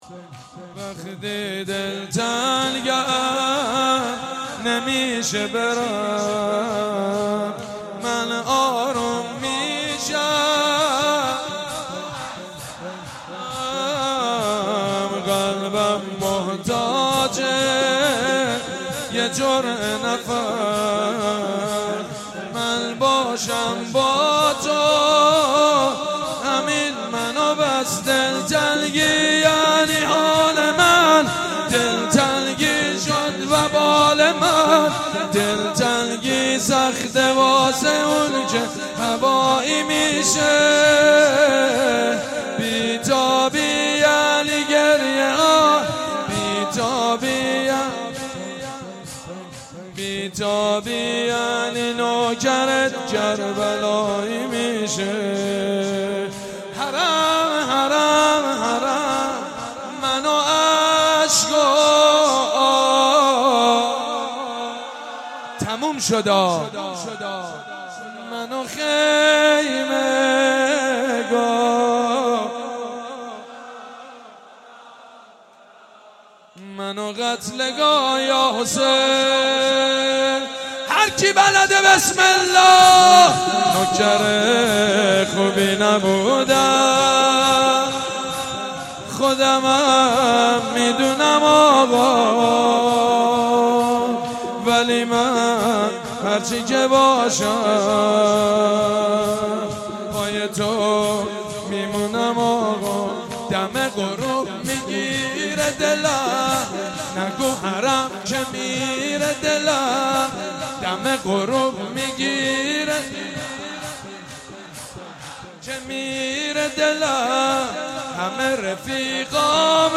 مداح : سیدمجید بنی‌فاطمه قالب : شور